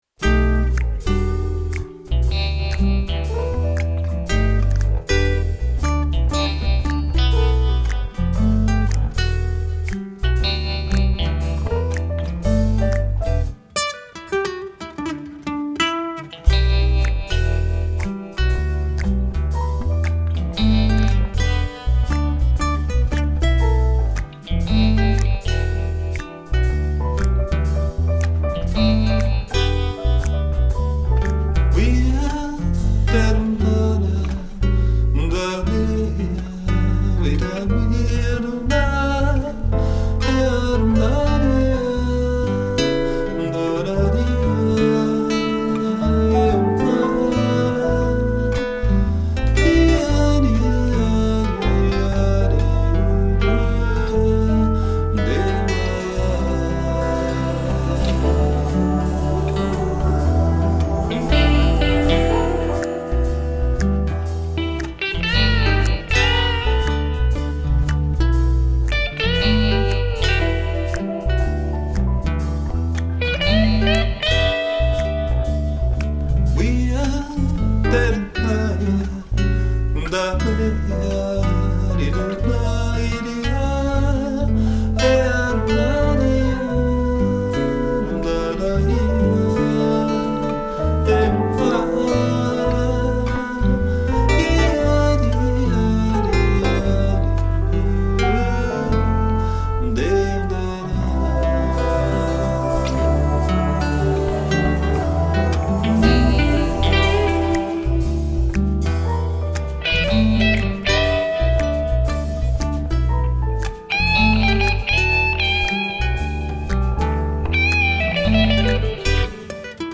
Instrumental violão solo guitar solo